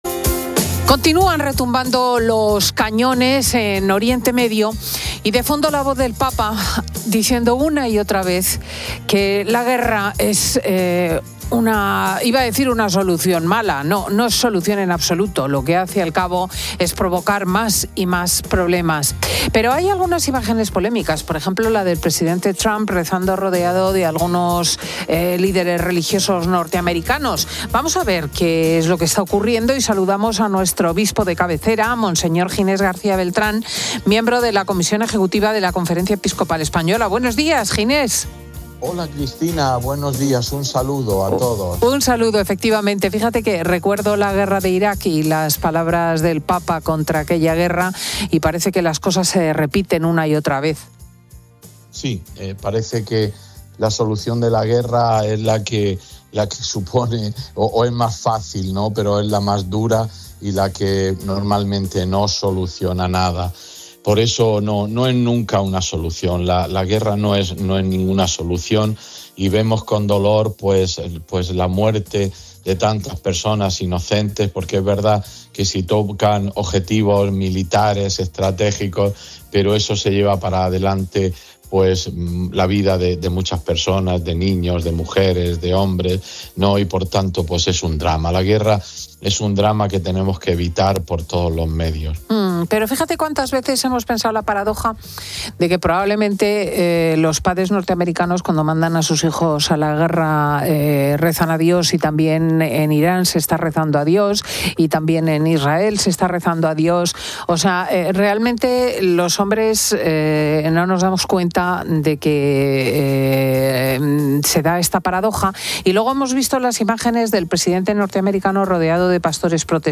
Durante la entrevista, la presentadora, Cristina López Schlichting, ha puesto sobre la mesa la paradoja de que en los conflictos, todos los bandos imploran al mismo Dios, ya sea en Estados Unidos, en Irán o en Israel.